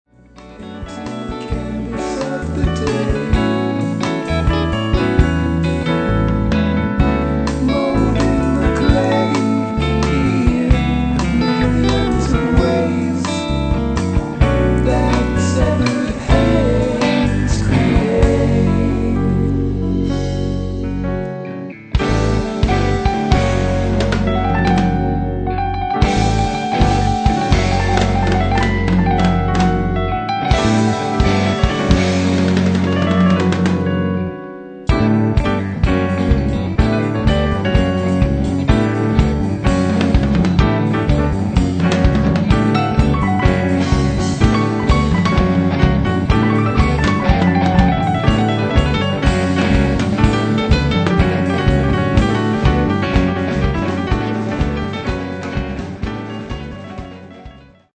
Experimental Rock Three Piece
- compositions, vocals, guitar
- drums
- bass, keyboards, guitar
recorded at Midtown Recording Studio